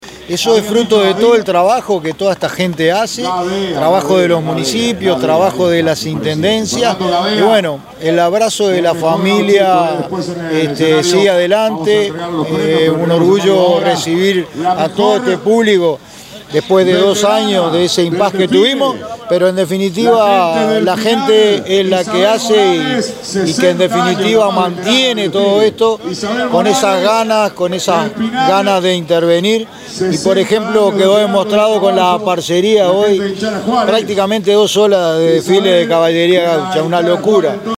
alcalde_del_municipio_de_soca_roberto_rodriguez.mp3